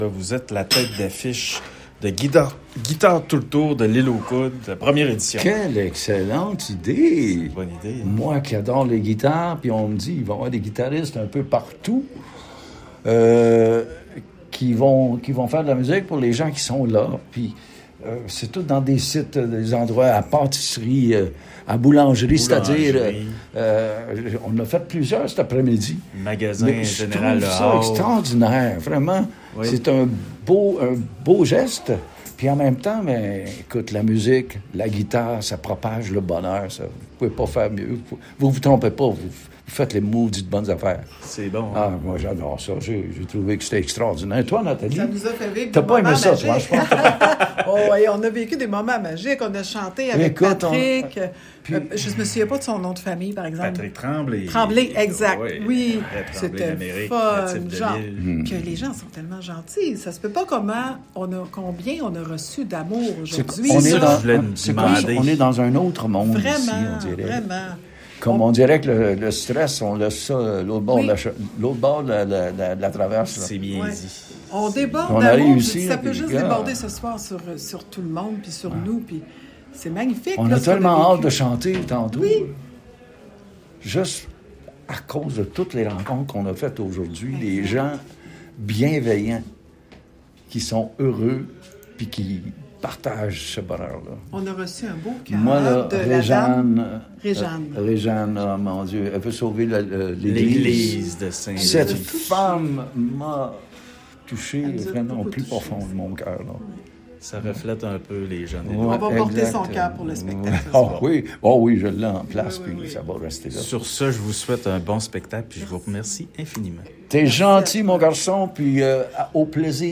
Entrevue exclusive